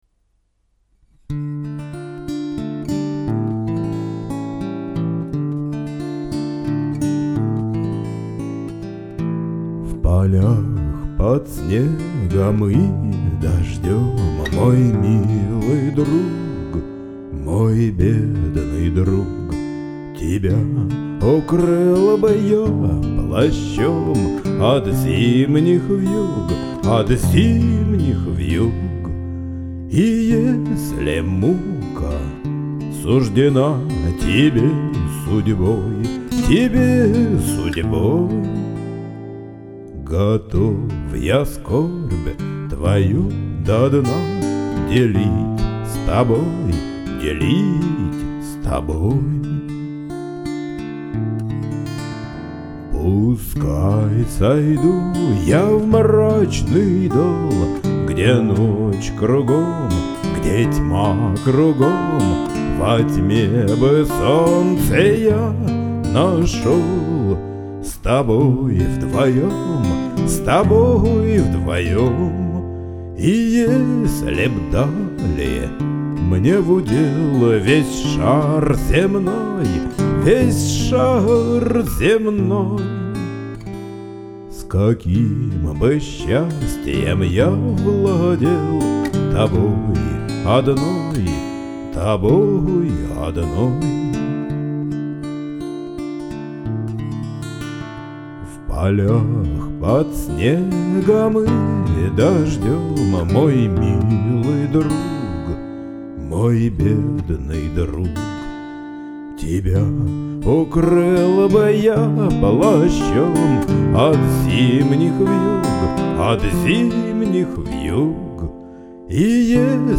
Аккорды Hm